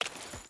Footsteps / Water
Water Chain Walk 3.wav